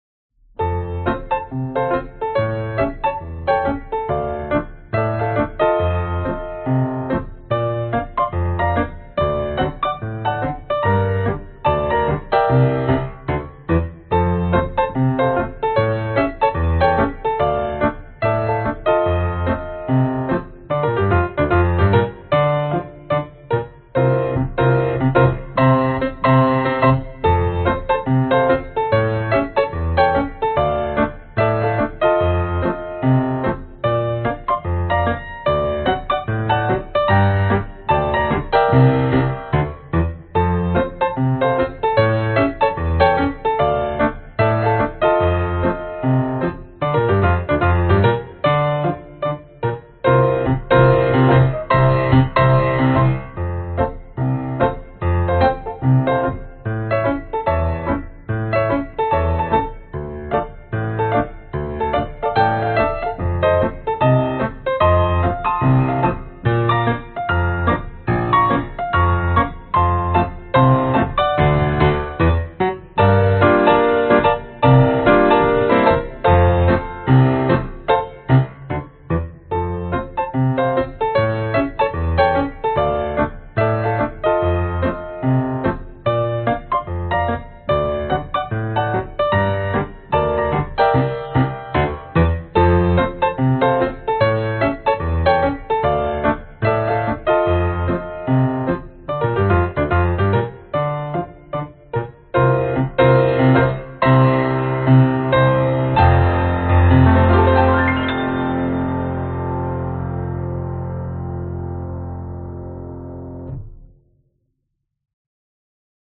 Tag: 圣诞 钢琴 假日 快乐 圣诞音乐 拉格泰姆 古典 易听 休闲 新年 原声 爵士 器乐